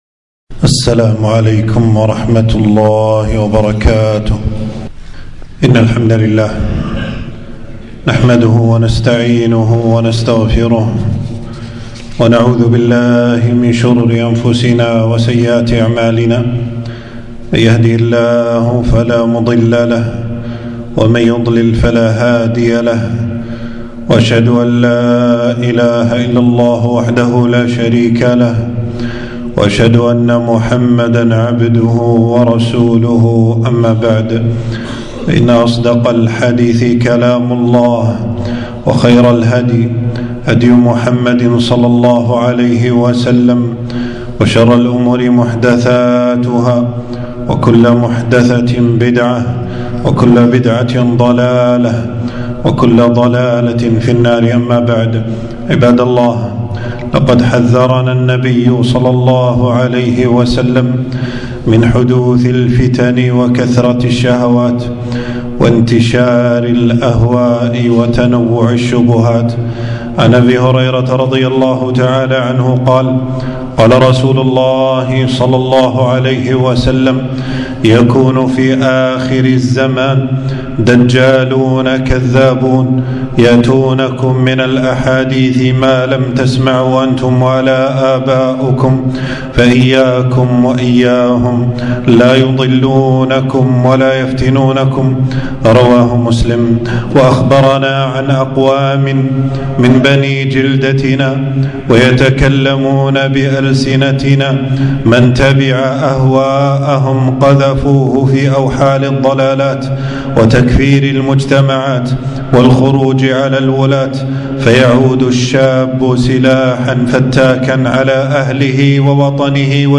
خطبة - تحذير الشباب من التطرف والإرهاب